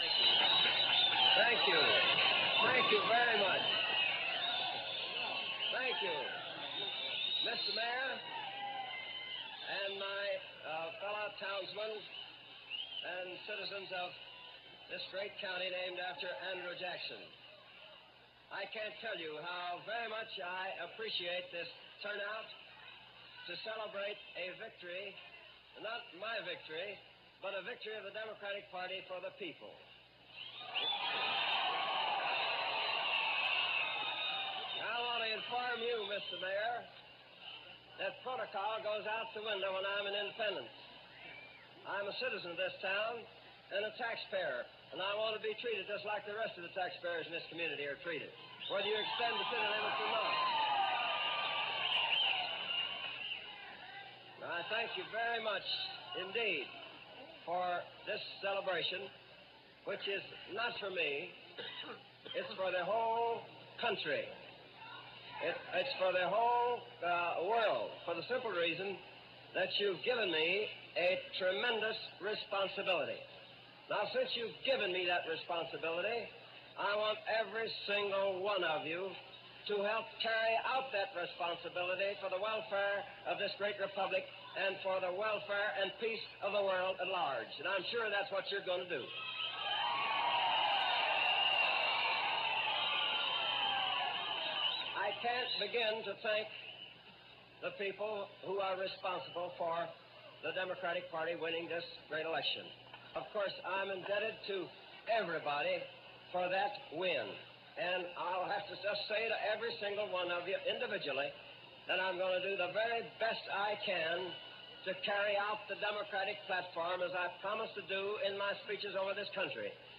File:Harry S. Truman 1948 Victory speech.ogg - Wikimedia Commons
Remarks by Harry S. Truman at the Victory Celebration in Independence, Missouri
victoryspeech.mp3